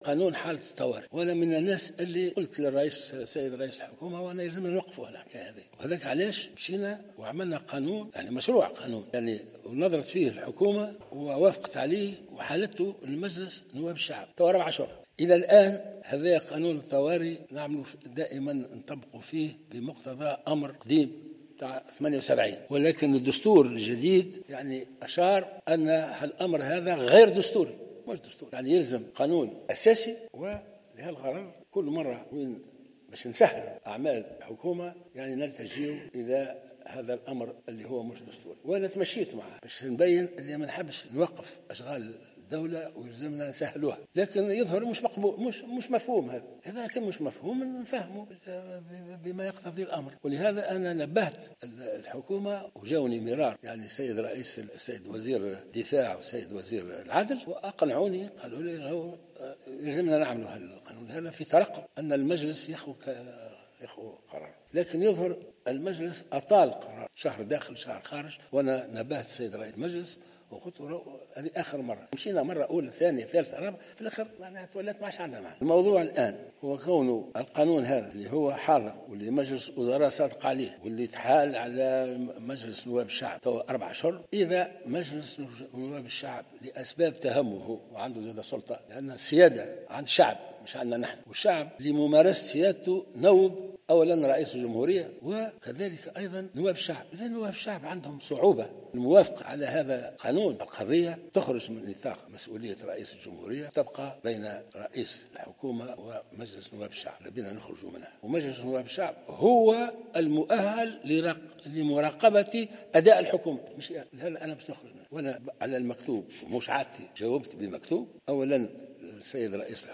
أكد رئيس الجمهورية الباجي قايد السبسي في كلمة ألقاها على هامش انعقاد مجلس الأمن القومي أن مجلس نواب الشعب هو المسؤول مستقبلا على قانون حالة الطوارئ.